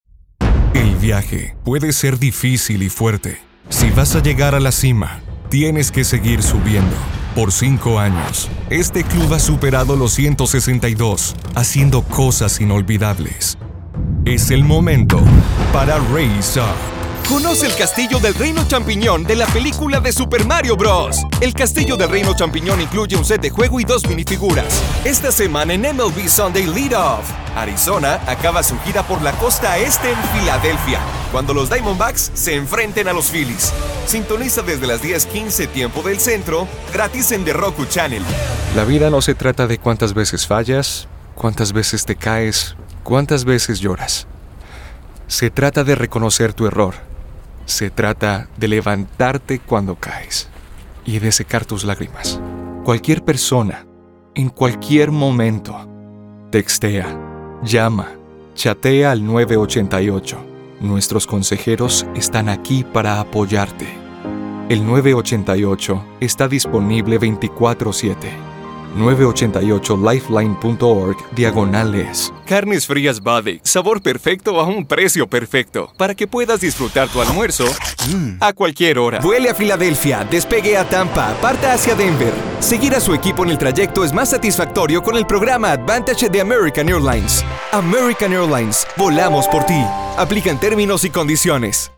Latin American Voice Over.
Very wide voice range.
Commercial Reel of campaings in the US, Mexico and LatAm.
Español Neutro Latino - Spanish Latin America - English Latino